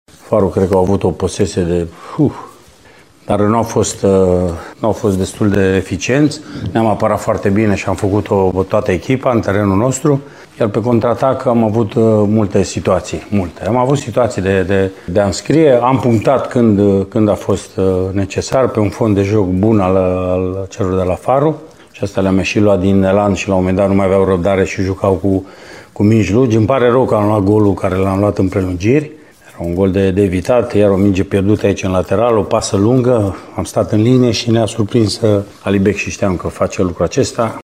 La conferința de presă de după meci, antrenorul utist Mircea Rednic a recunoscut posesia net superioară a adversarilor, dar s-a declarat bucuros că a reușit să câștige, cu execuții reușite în momentele potrivite: